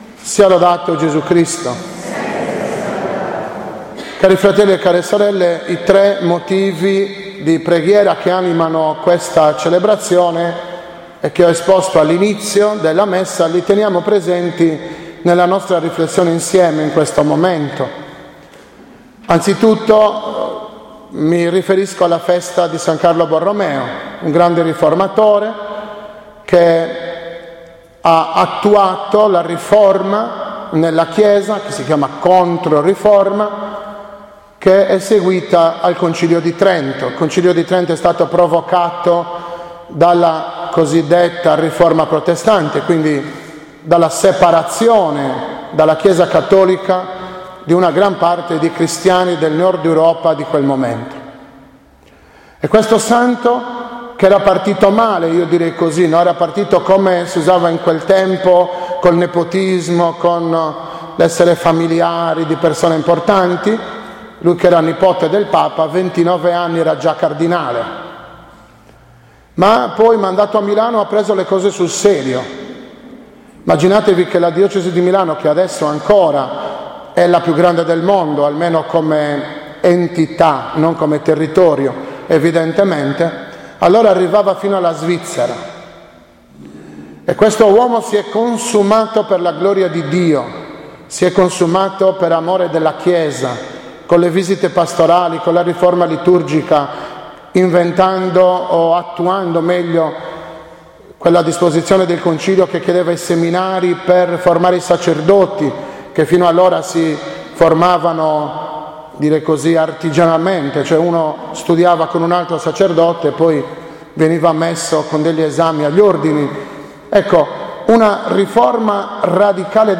04.11.2016 – OMELIA DELL’OTTAVA DEI DEFUNTI